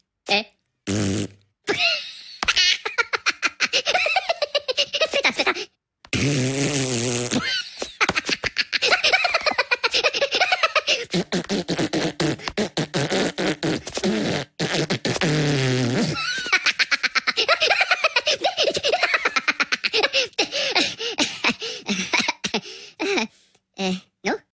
Kategorien Lustige